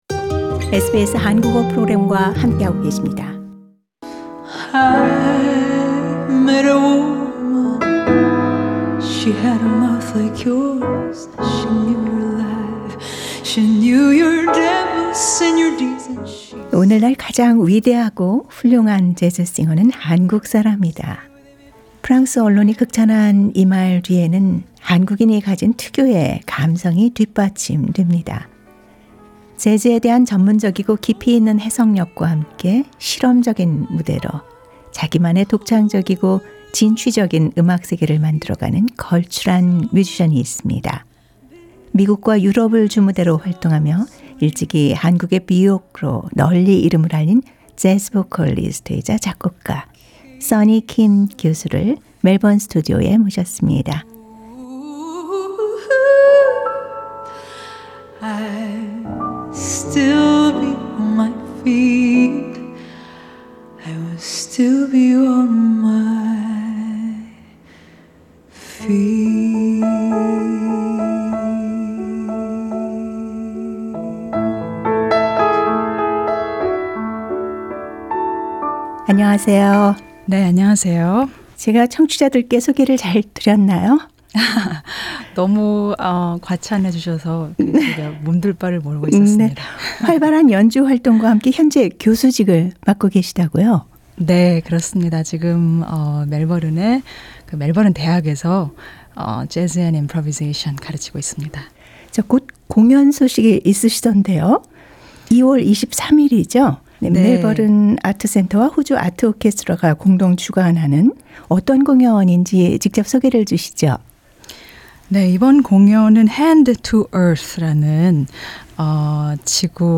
Vocalist composer and improviser